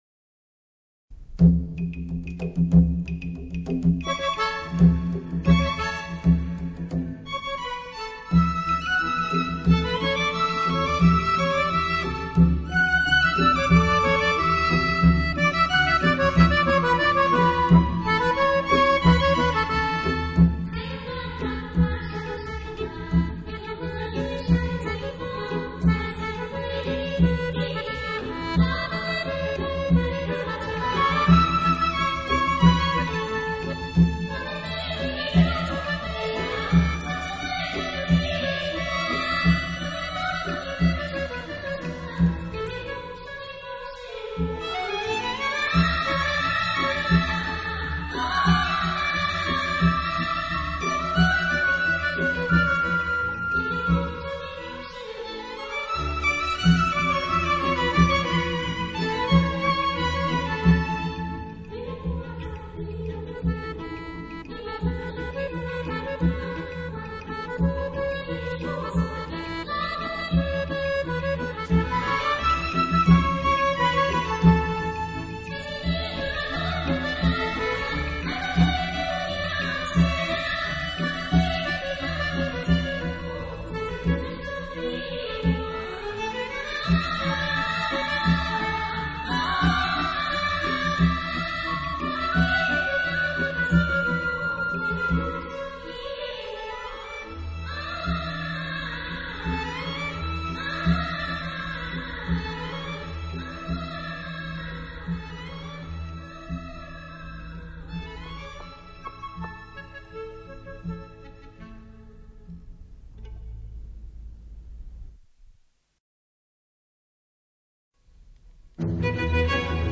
大陆唱片史上出版的第一盘立体声音带